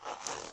马桶盖打开2
描述：在带有Android平板电脑的浴室中录制，并使用Audacity进行编辑。